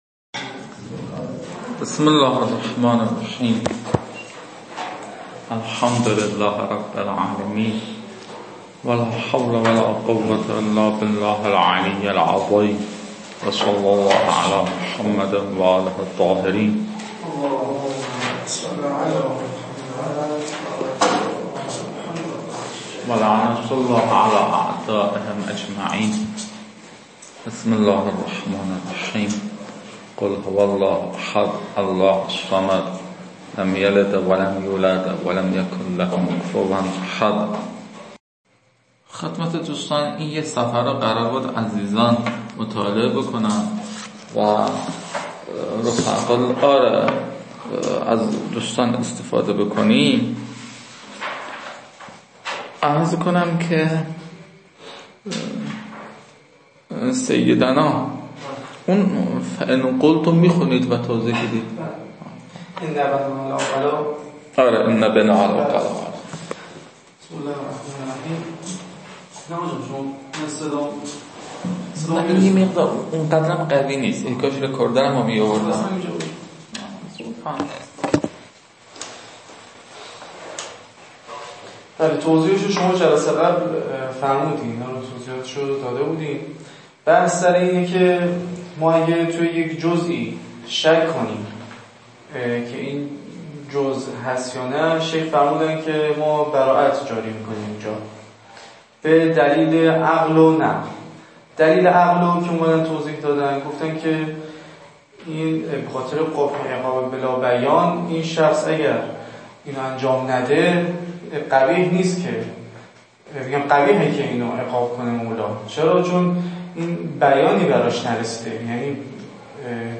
این فایل ها مربوط به تدریس مبحث برائت از كتاب فرائد الاصول (رسائل) متعلق به شیخ اعظم انصاری رحمه الله می باشد